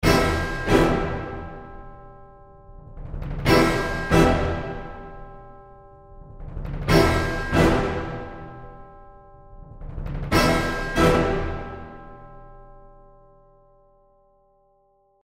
In order to get this working well, I’ll do slight humanizing and some velocity changes.
You surely can notice how much the sound changed; everything sounds more spacious and more tense.
OrchestralHits_WholeOrchestraHumanized.mp3